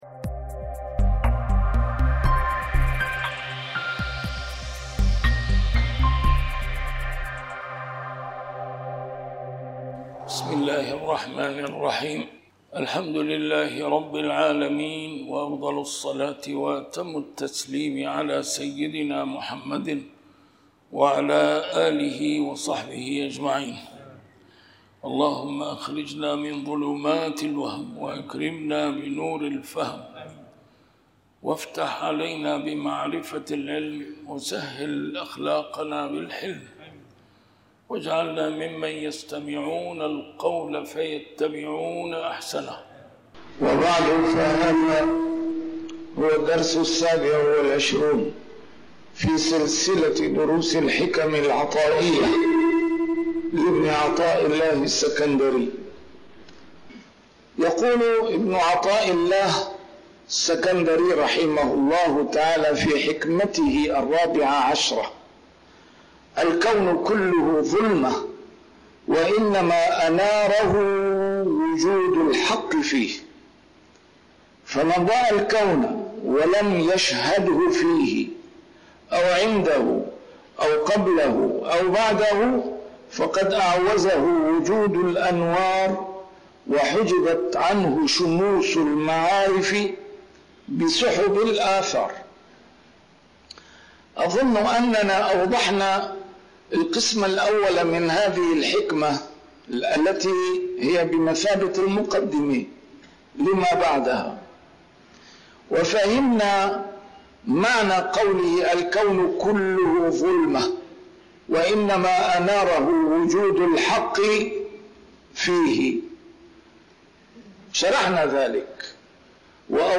A MARTYR SCHOLAR: IMAM MUHAMMAD SAEED RAMADAN AL-BOUTI - الدروس العلمية - شرح الحكم العطائية - الدرس رقم 27 شرح الحكمة 14